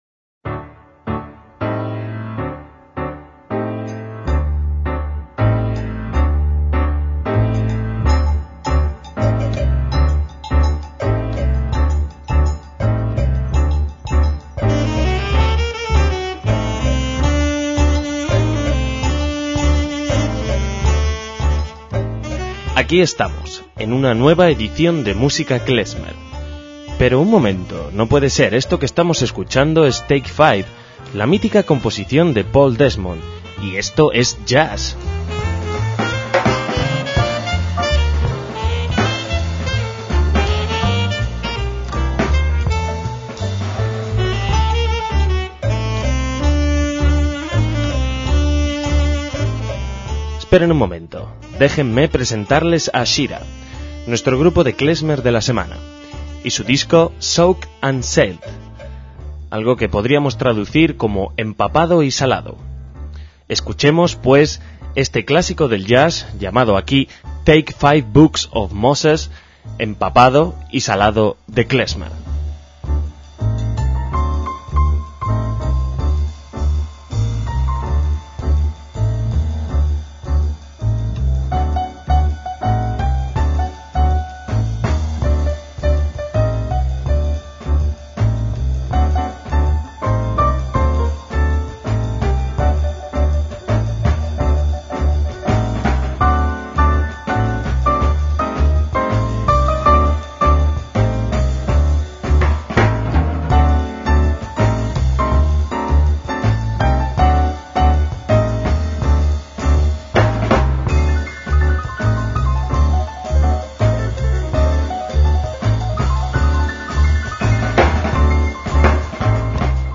clarinete, saxo y flauta dulce
batería
piano y teclados
contrabajo